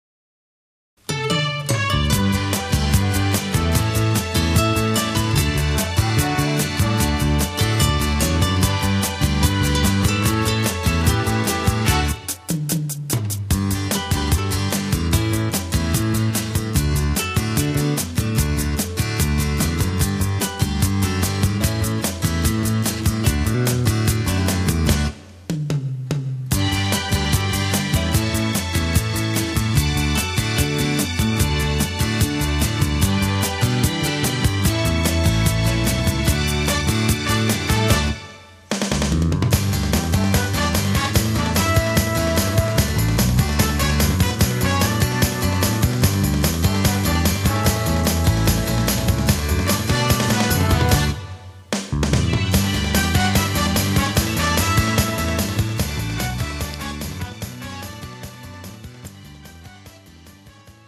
MR 고음질 반주 다운로드.